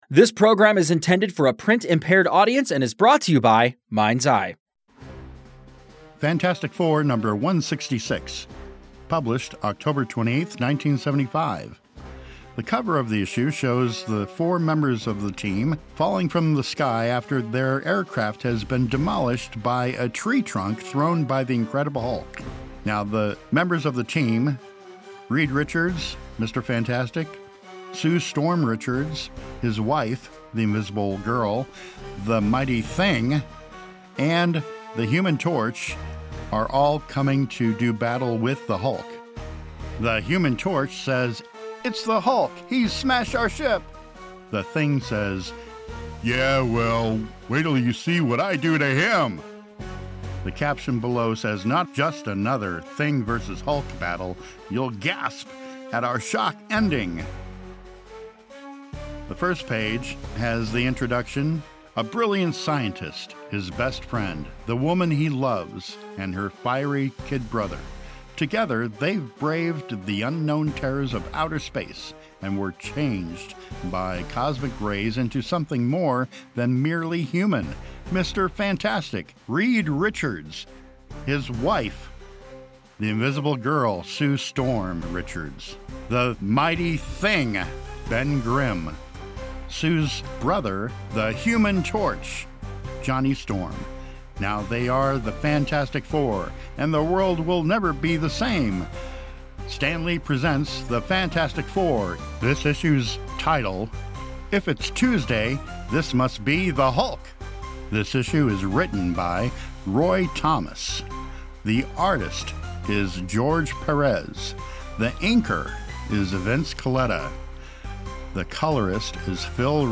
Daredevil, The Hulk, and many more of your favorite superheros are brought to live with our audio described and audio produced comics!